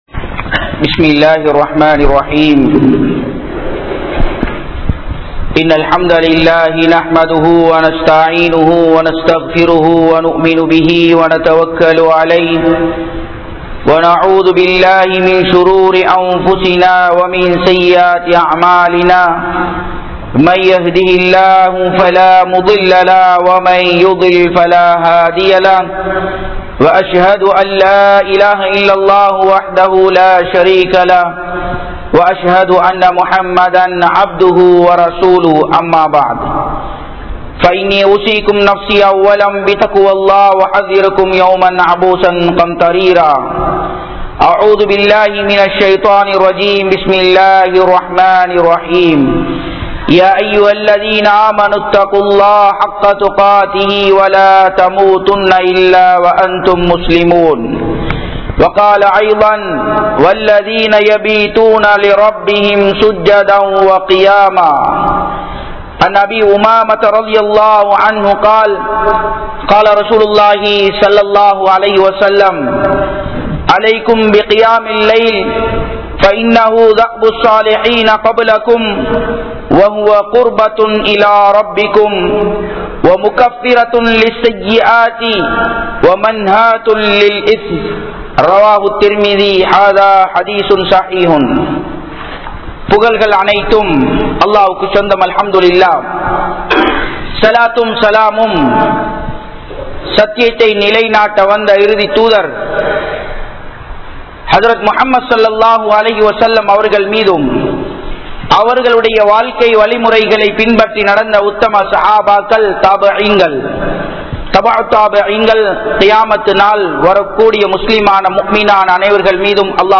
Thahajjuthku Samanaana Nanmaihal (தஹஜ்ஜூத்துக்கு சமனான நன்மைகள்) | Audio Bayans | All Ceylon Muslim Youth Community | Addalaichenai